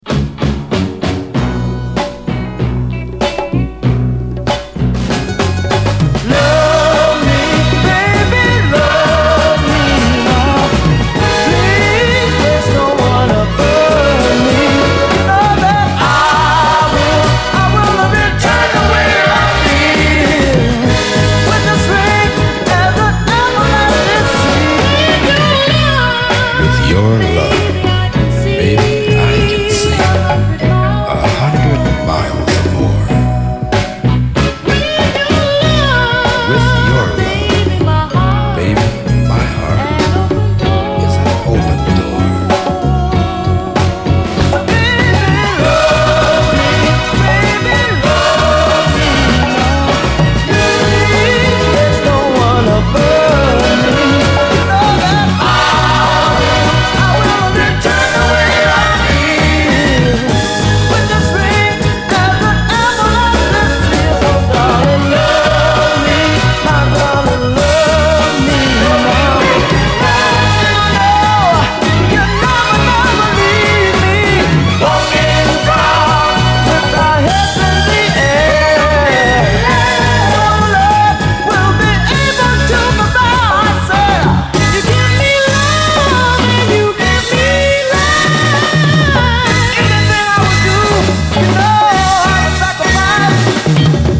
SOUL, 70's～ SOUL, 7INCH
熱くこみ上げるナイス・ヴォーカル・グループ・ソウル！